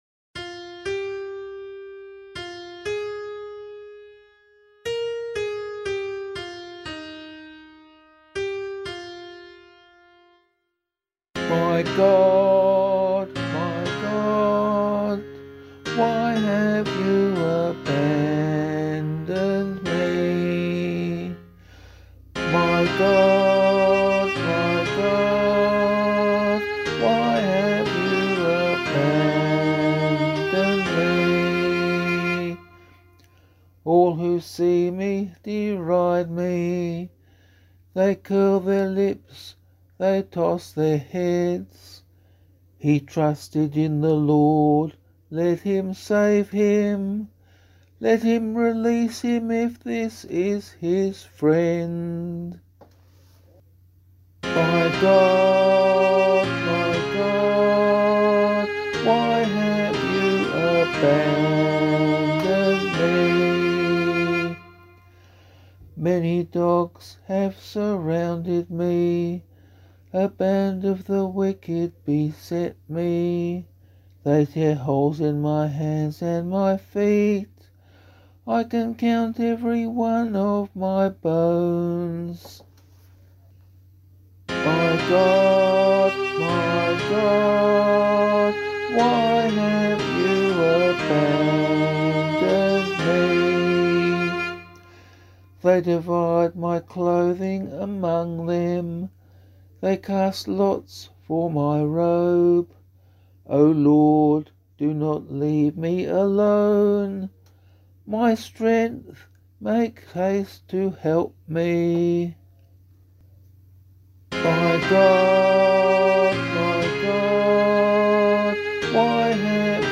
018 Palm Sunday Psalm [LiturgyShare 2 - Oz] - vocal.mp3